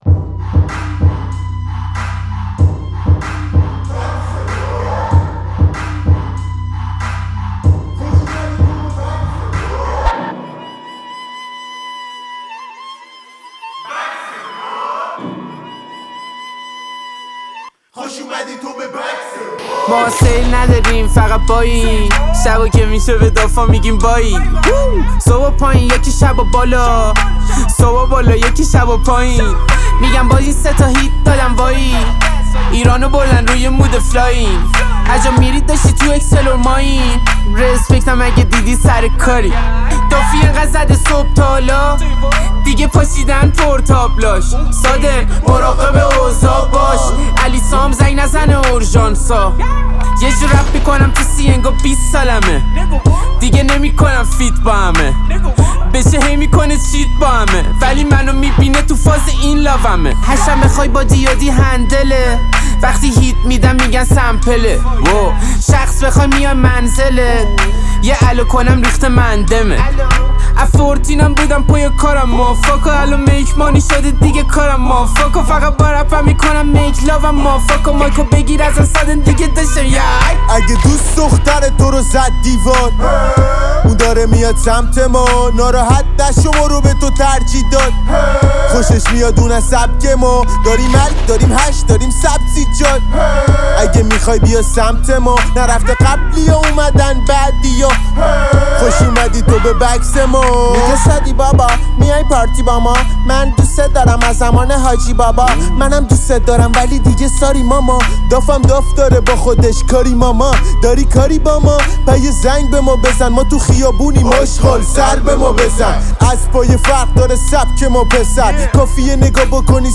ترک گنگ و پرانرژی